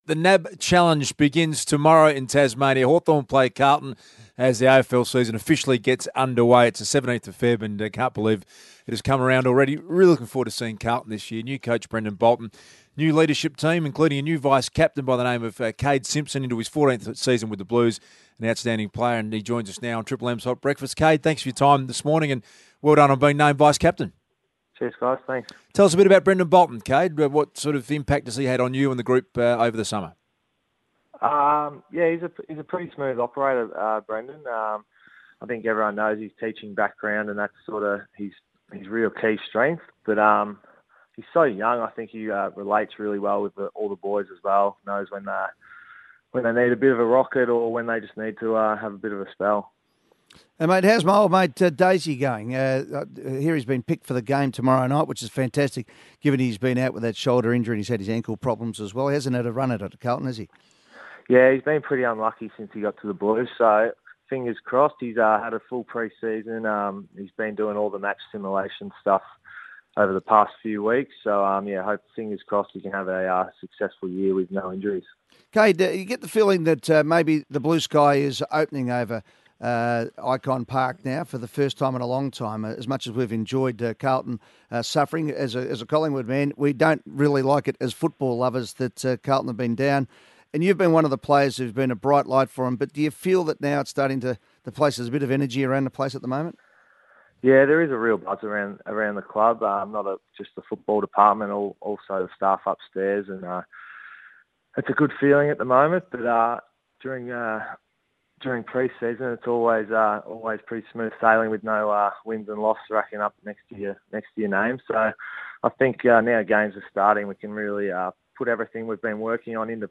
Carlton vice-captain Kade Simpson speaks on Triple M's Hot Breakfast ahead of the Blues' 2016 NAB Challenge opener.